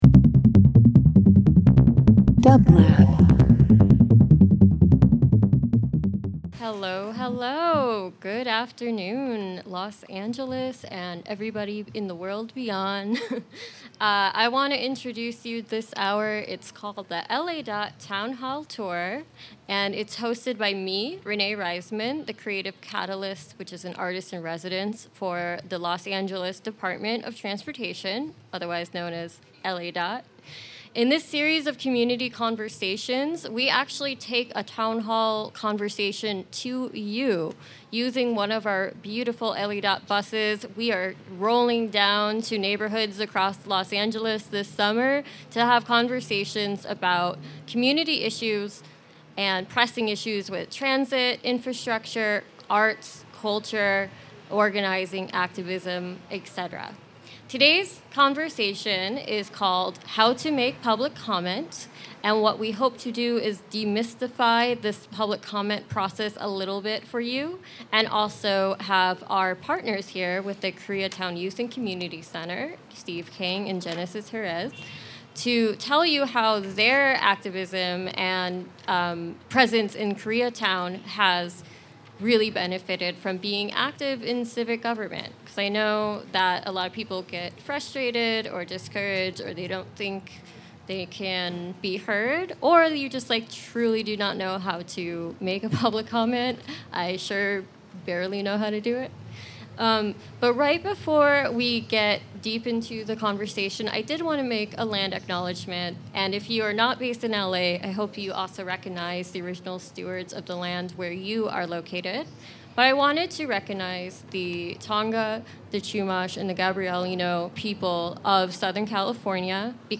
LADOT 07.10.21 Interview Talk Show The Mobile Town Hall series